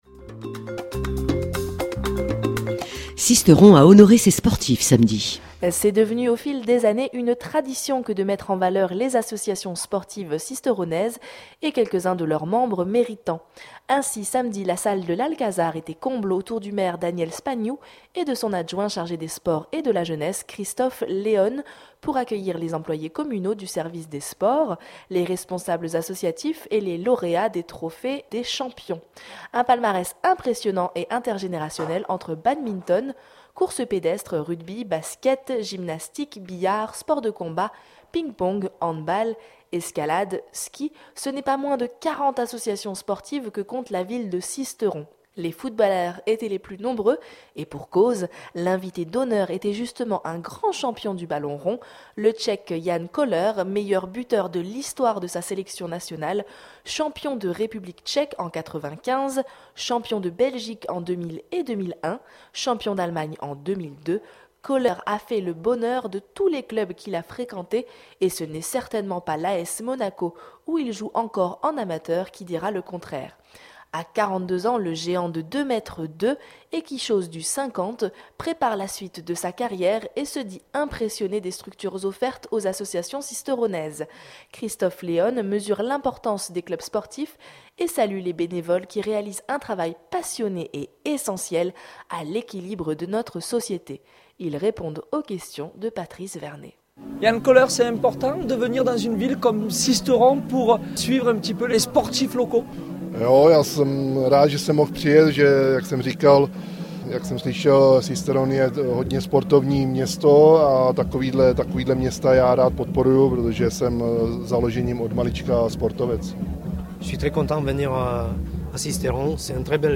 Ainsi samedi la salle de l’Alcazar était comble autour du maire Daniel Spagnou et de son adjoint chargé des sports et de la jeunesse Christophe Léone, pour accueillir les employés communaux du service des sports, les responsables associatifs et les lauréats des Trophées des champions.